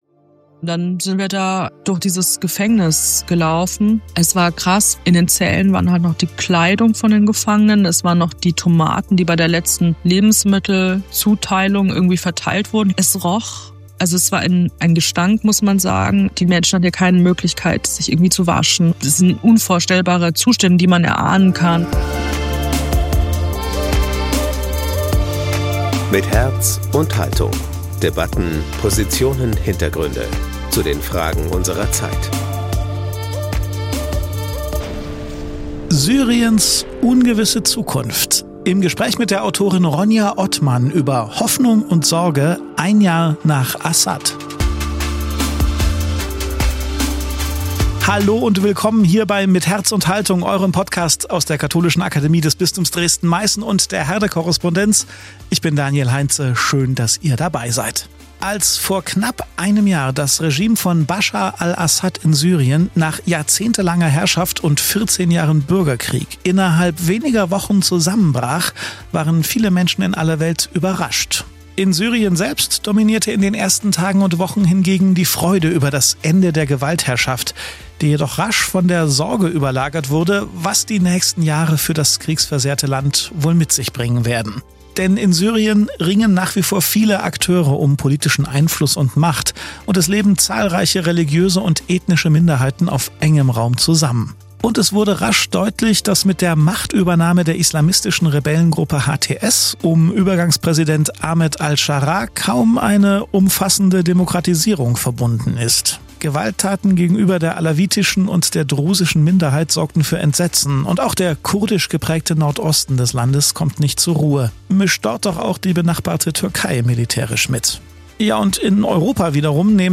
Auch ein Jahr nach dem Zusammenbruch des Regimes von Baschar al-Assad ist die Zukunft Syriens keineswegs gewiss. Für einen Überblick über die komplexe Situation und einen Eindruck, wie sich die Situation vor Ort darstellt, sprechen wir in dieser Folge mit der Journalistin und Autorin Ronya Othmann.